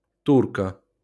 Turka (Ukrainian: Турка, IPA: [ˈturkɐ]